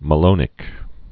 (mə-lōnĭk, -lŏnĭk)